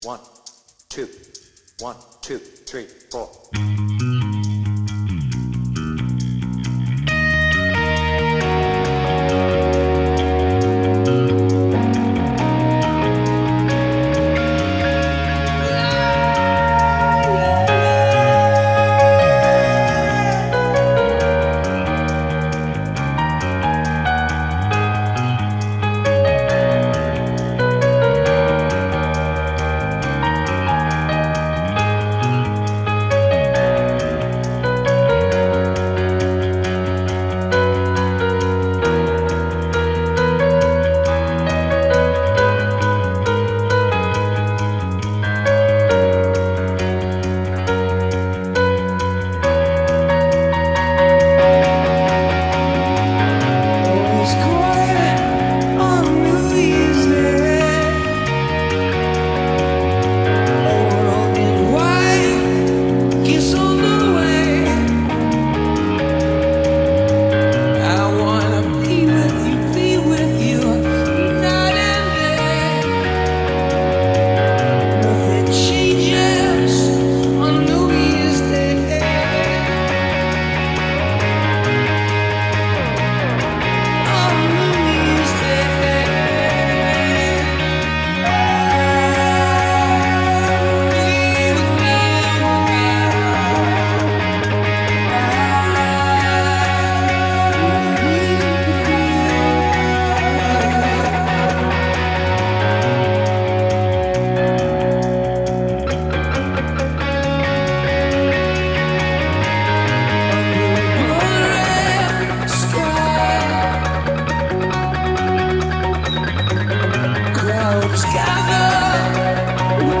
BPM : 136
Tuning : Eb
With vocals